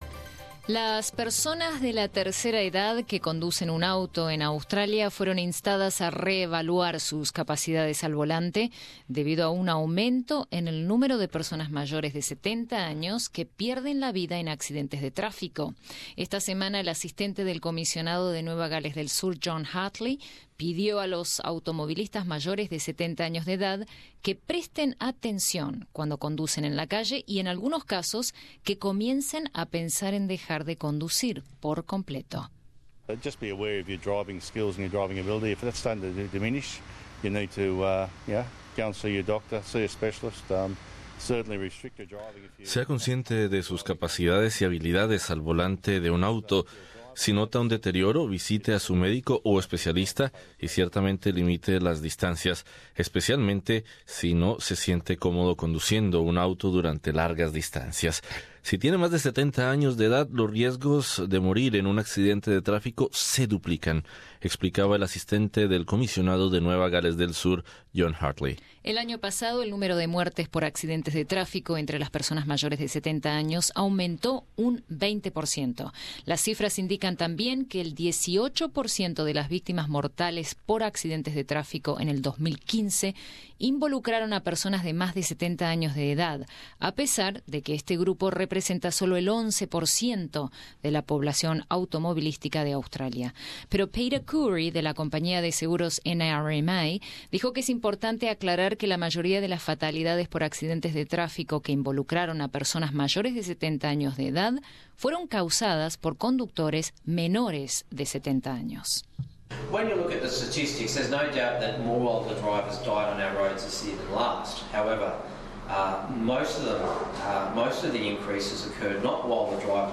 ¿Habría que poner un límite de edad a la conducción en Australia?El Asistente del Comisionado de la Policía de Nueva Gales del Sur, pidió a los conductores mayores de 70 años, que comiencen a pensar en dejar de conducir. Escucha nuestro informe con varios protagonistas, incluyendo un conductor de Melbourne de 82 años de edad que no está completamente en desacuerdo con la propuesta.